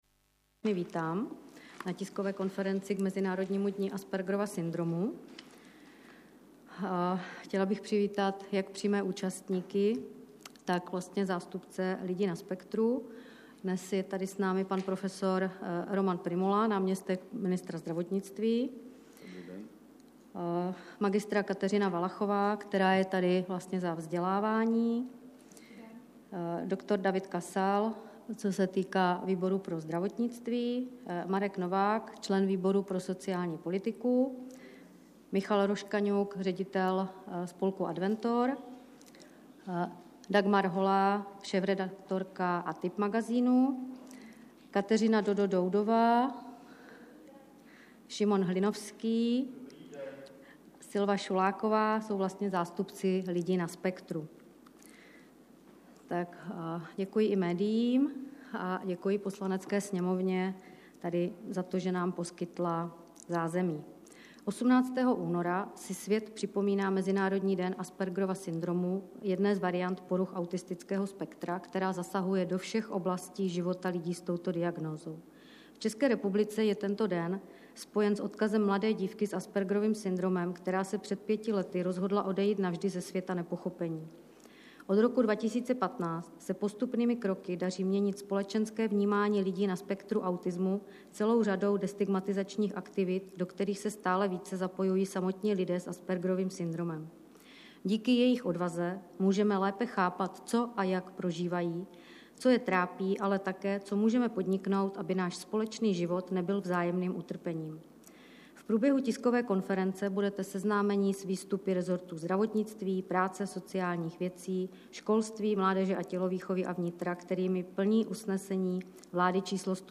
Dne 18.února 2020 se zúčastnil poslanec MUDr. David Kasal, MHA tiskové konference k mezinárodnímu dni Aspergerova syndromu, která se uskutečnila v poslanecké sněmovně v Praze.
Poslechněte si zvukový záznam z této konference.